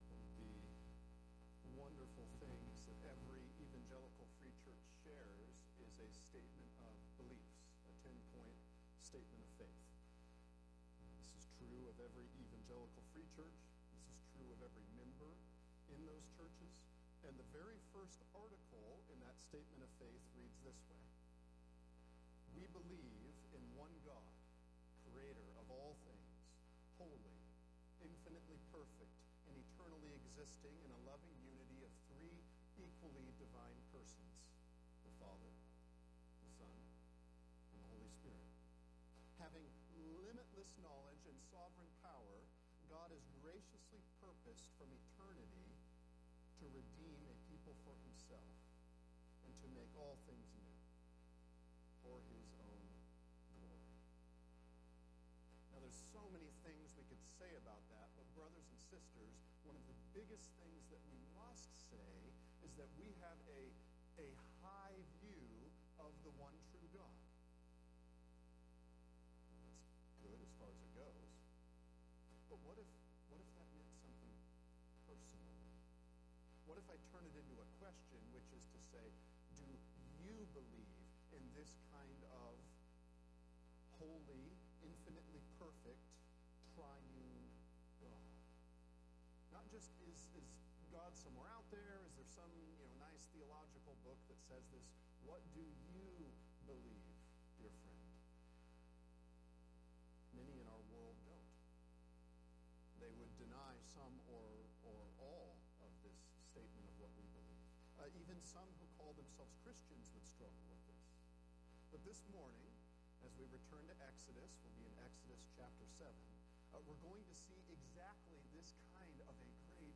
Exodus 7:1-13 That All May Know – Sermons